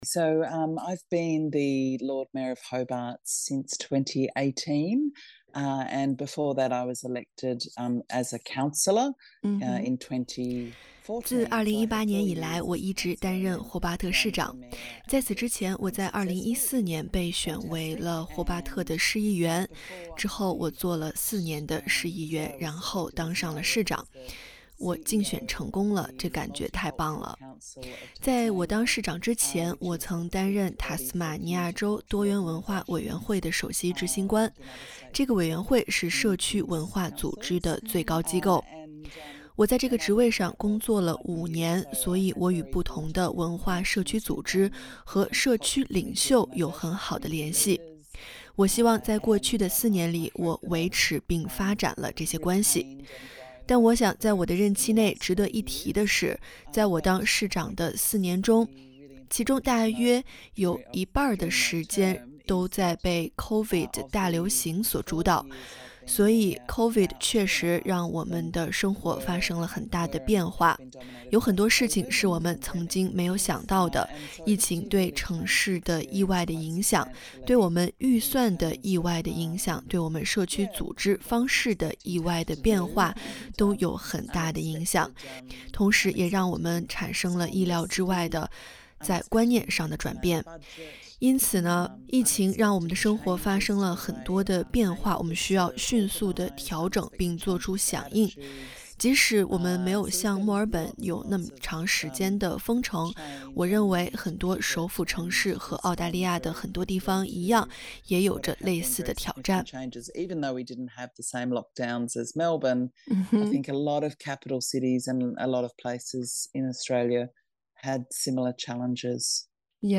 Anna在采访中表示，在她担任市长的四年中，其中大约一半的时间被COVID大流行所主导，所以这也让她经历了前所未有的挑战。